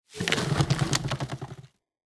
tree-leaves-2.ogg